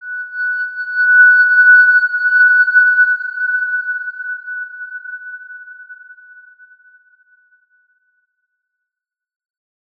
X_Windwistle-F#5-pp.wav